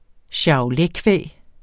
Udtale [ ɕɑoˈlε- ]